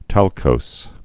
(tălkōs) also talc·ous (-kəs) or talck·y (-kē)